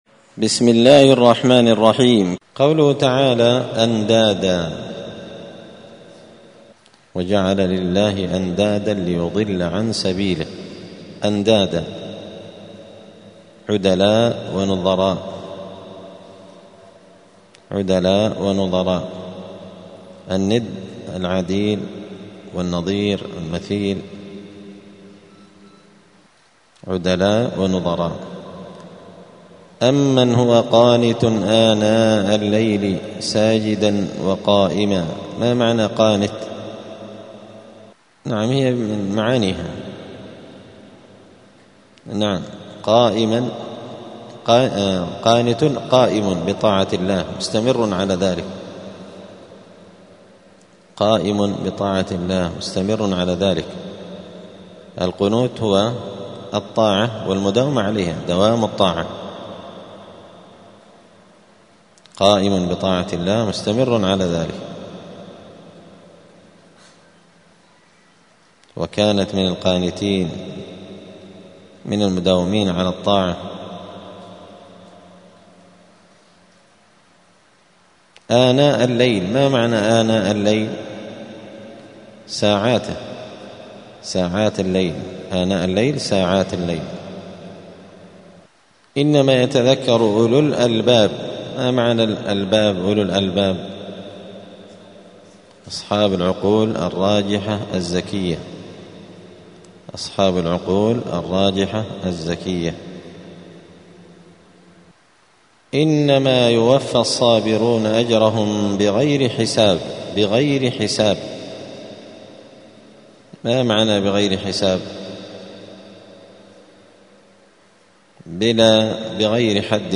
الثلاثاء 17 شوال 1446 هــــ | الدروس، دروس القران وعلومة، زبدة الأقوال في غريب كلام المتعال | شارك بتعليقك | 21 المشاهدات